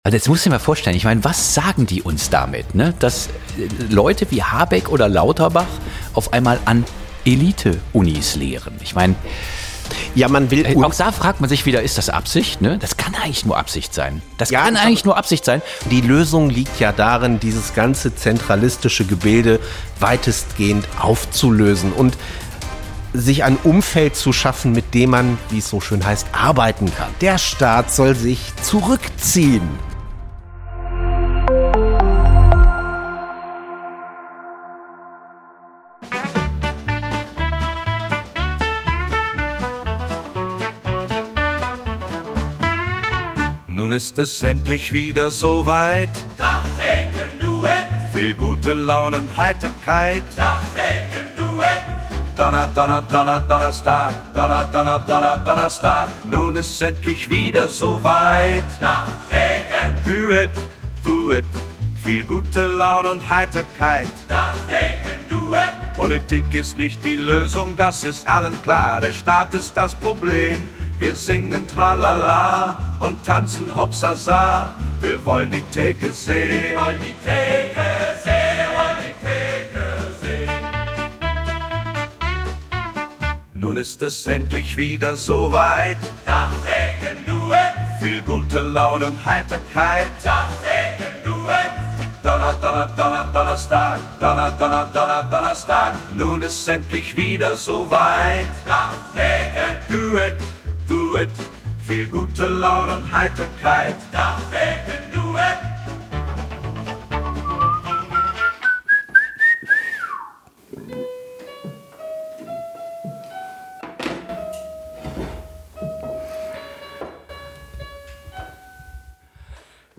Kleine Redaktionskonferenz am Tresen
Wie immer werden in dieser Reihe an der ef-Theke spontan aktuelle Entwicklungen und brennende Themen beleuchtet.